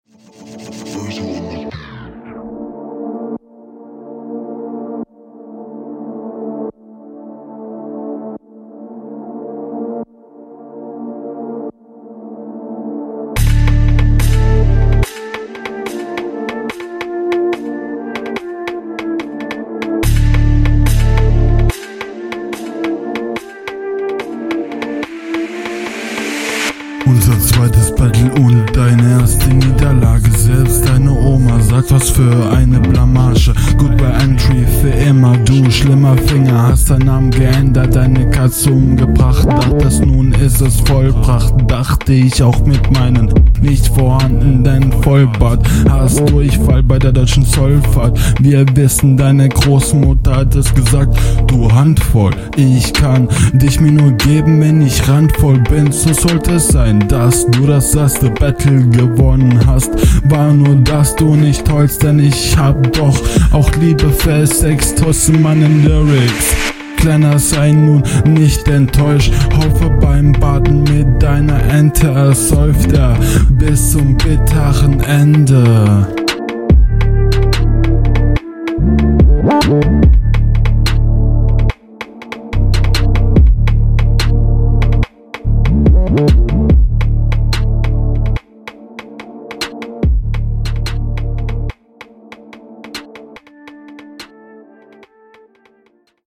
Oy ein Drill Beat mal gucken wie du drauf kommst.